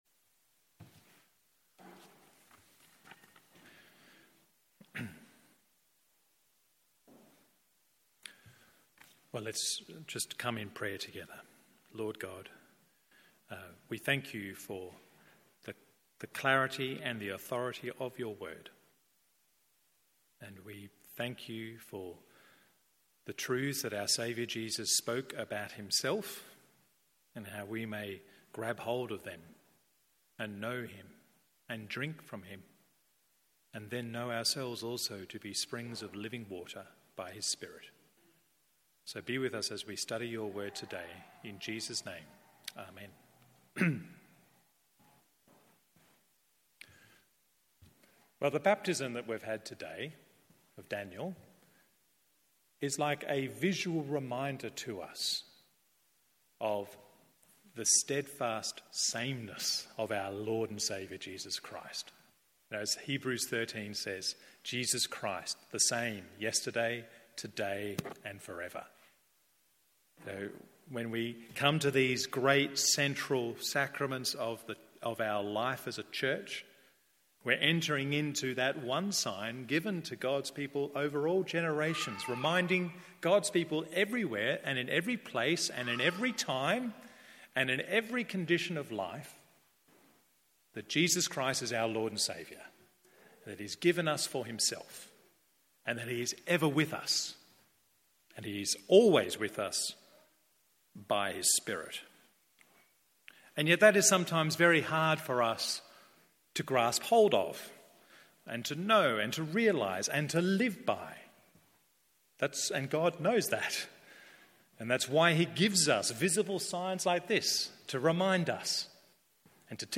MORNING SERVICE John 7:32-53…